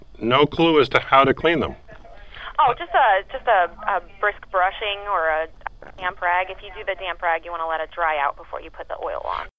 A slightly more exotic method is a microphone such as the Olympus TP-7 or TP-8 and a small personal recorder. You place the microphone in your ear under your headphones, start the recorder and do everything else normally.